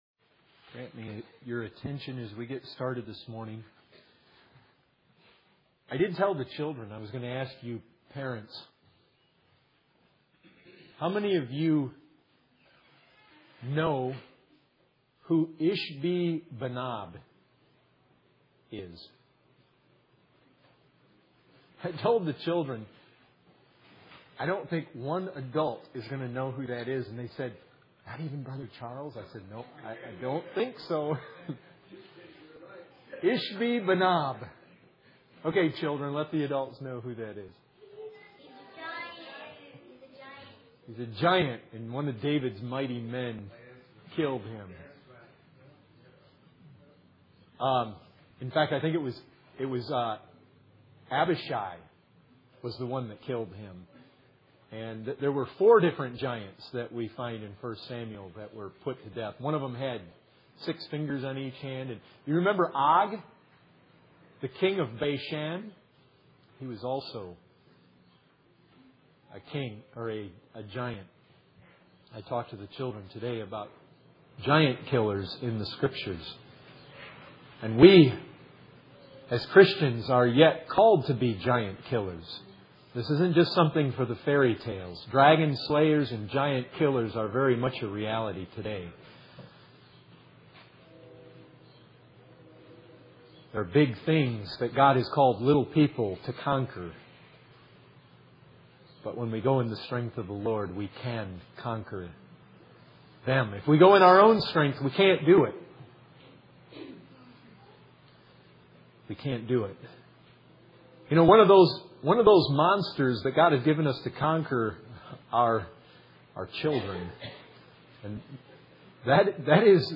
In this sermon, the preacher emphasizes the importance of relying on the strength of the Lord to conquer the challenges and obstacles in life.